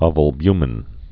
(ŏvəl-bymĭn, ōvəl-)